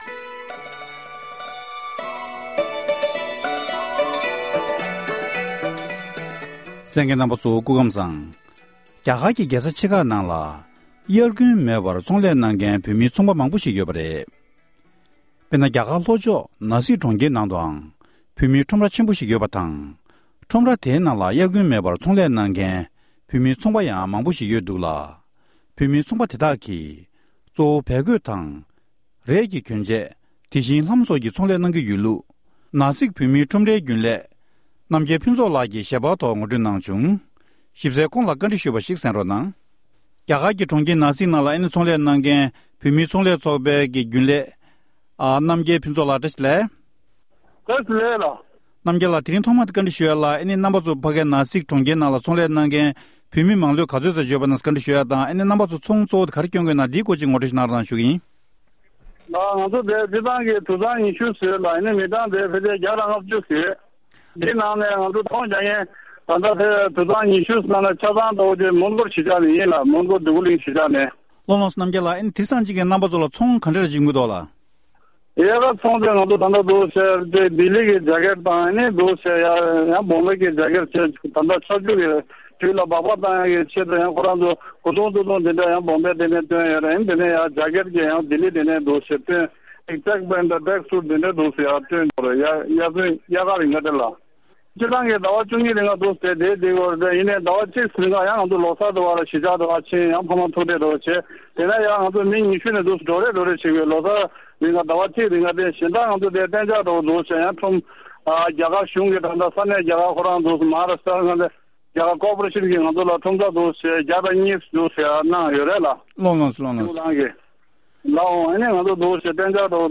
བཀའ་འདྲི